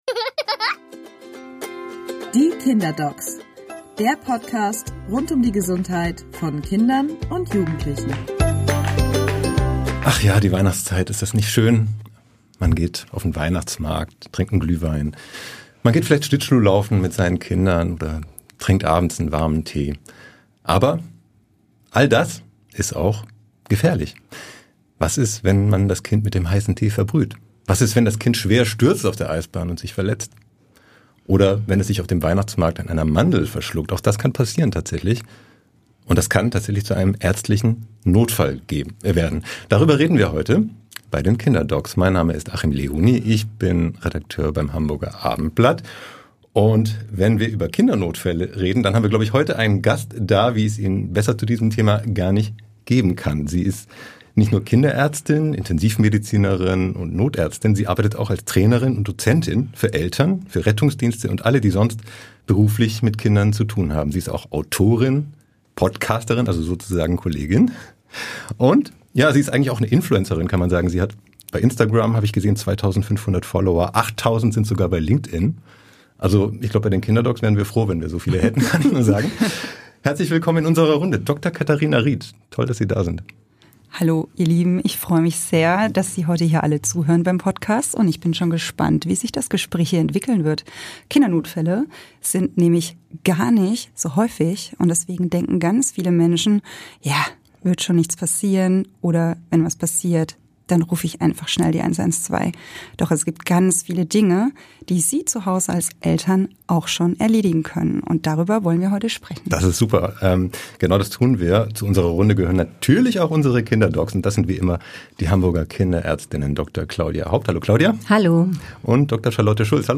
Im Gespräch mit den Hamburger Ärztinnen